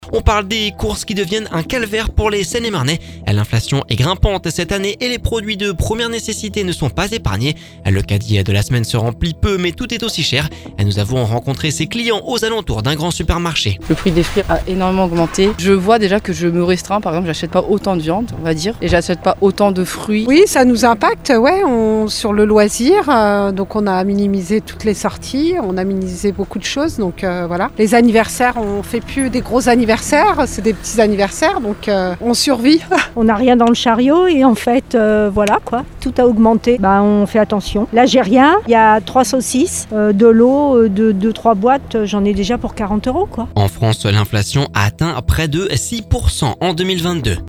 Le caddie de la semaine se rempli peu mais est tout aussi cher. Nous avons rencontré ces client aux alentours d’un grand supermarché…En France l’inflation a atteint les 5,9% en 2022 !